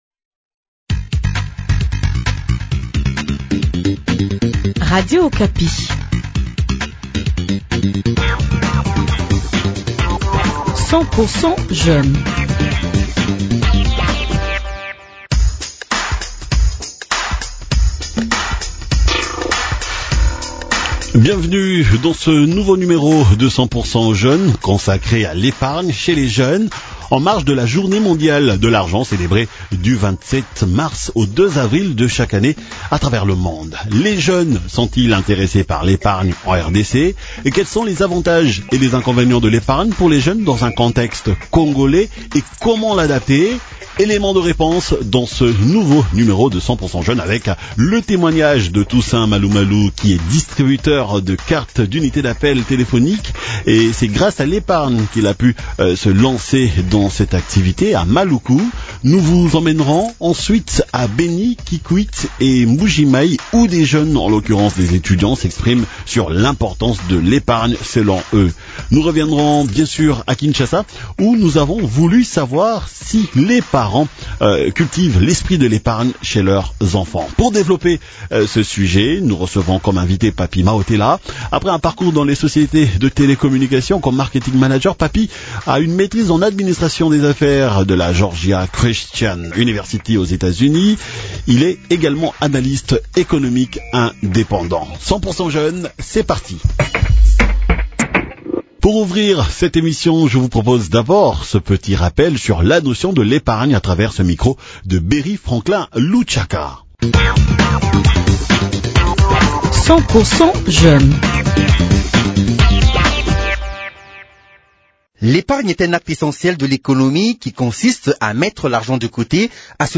Quelques eunes de Beni, Kikwit, Kinshasa et Mbuji Mayi s’expriment aussi à ce sujet .